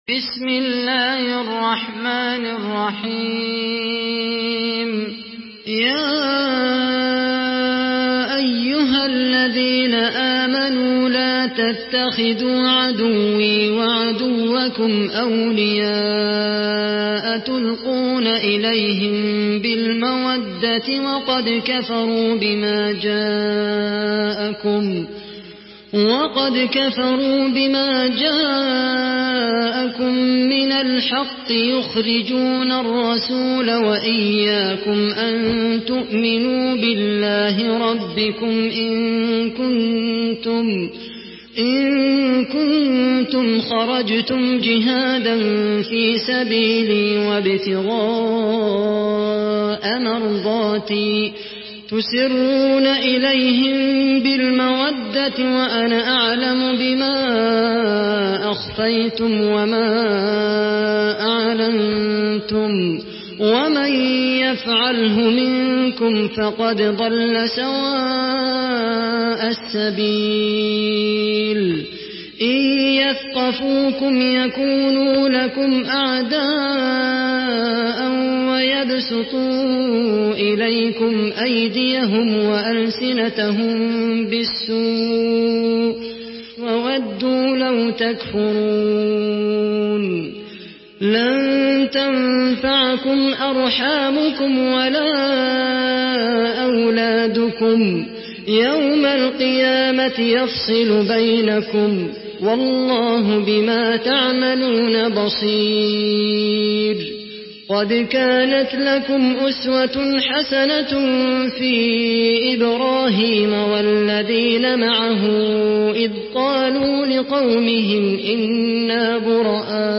سورة الممتحنة MP3 بصوت خالد القحطاني برواية حفص
سورة الممتحنة MP3 بصوت خالد القحطاني برواية حفص عن عاصم، استمع وحمّل التلاوة كاملة بصيغة MP3 عبر روابط مباشرة وسريعة على الجوال، مع إمكانية التحميل بجودات متعددة.
مرتل